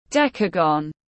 Hình thập giác tiếng anh gọi là decagon, phiên âm tiếng anh đọc là /ˈdek.ə.ɡən/.
Decagon /ˈdek.ə.ɡən/